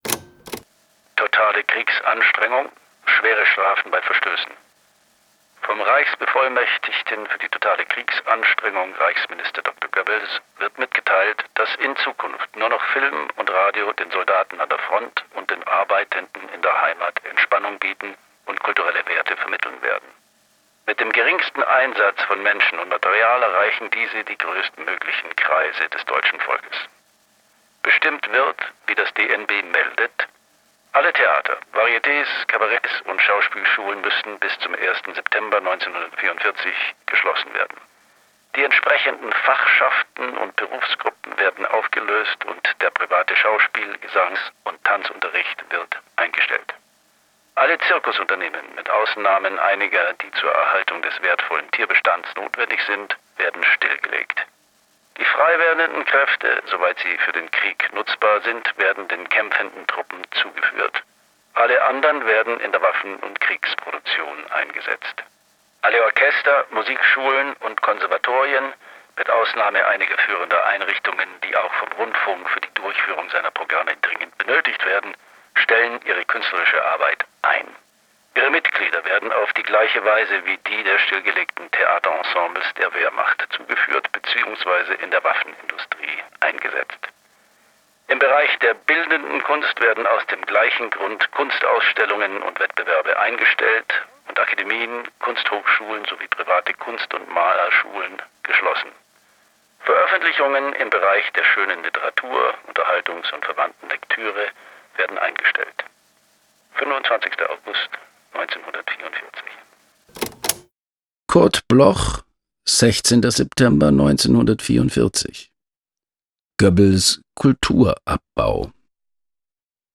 performed by August Zirner